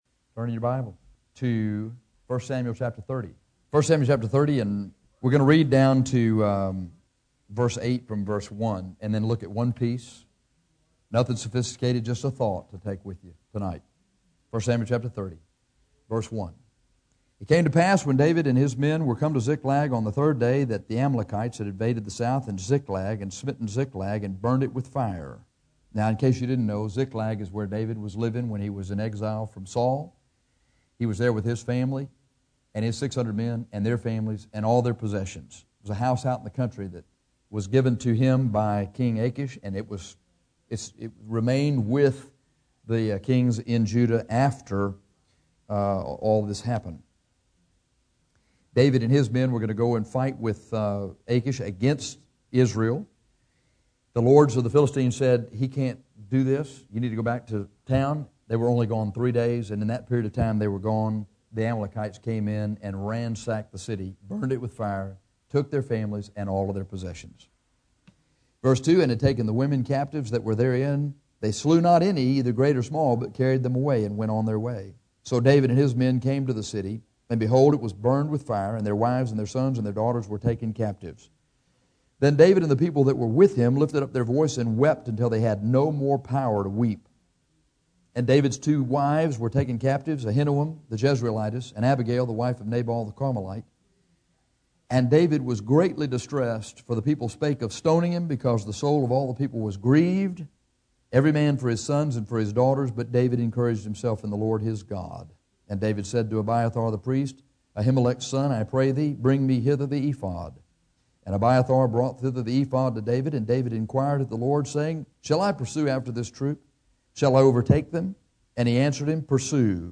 Sermons 1 Sam. 30